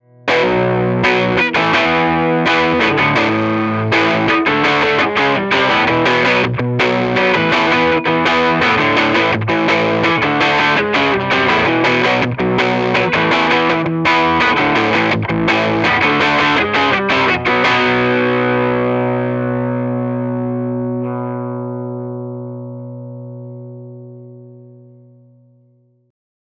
18 Watt v6 - EL84 Dirty Classic Lead 80
Note: We recorded dirty 18W tones using both the EL84 and 6V6 output tubes.